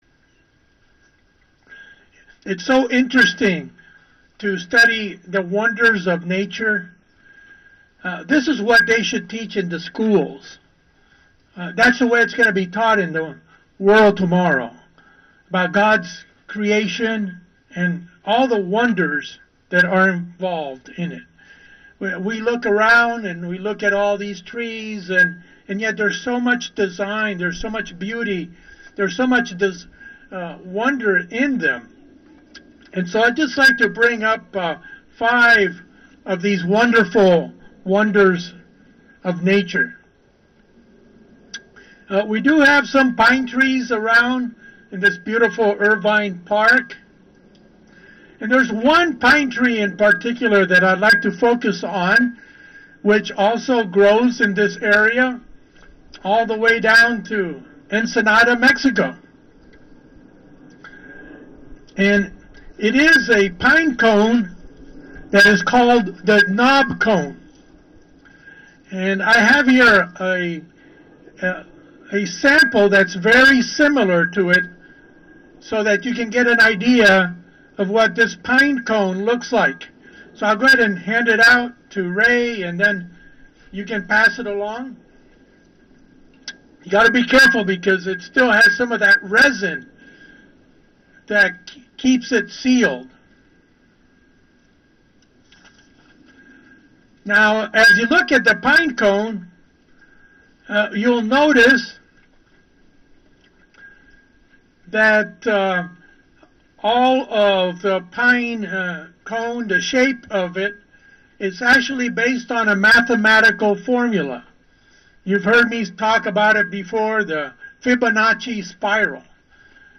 For this Sabbath-In-The-Park, we will consider five recent discoveries in nature that the science community views with amazement?